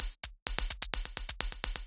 Index of /musicradar/ultimate-hihat-samples/128bpm
UHH_ElectroHatA_128-01.wav